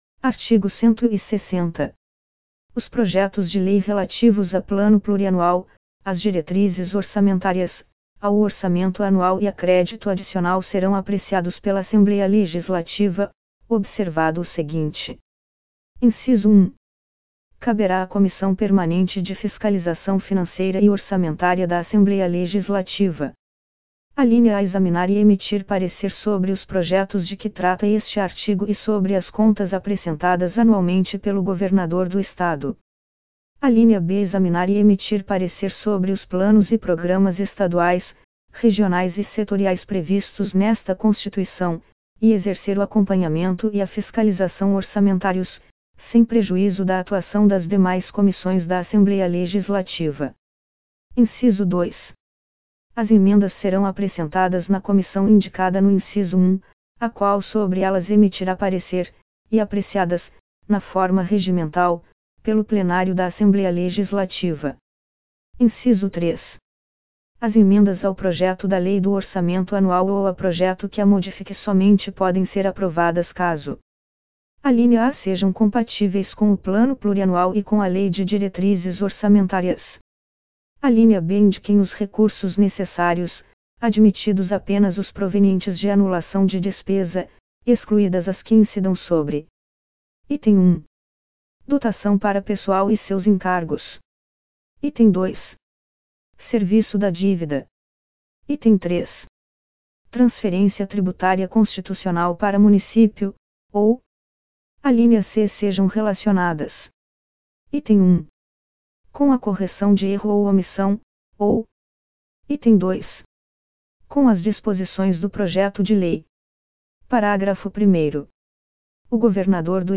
Os textos completos da Constituição do Estado de Minas Gerais e do Regimento Interno da Assembleia Legislativa podem ser consultados também na forma de áudio.